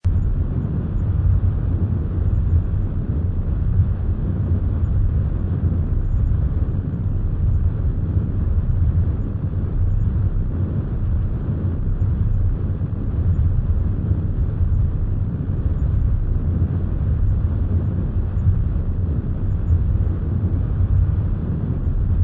thrusters_loop.ogg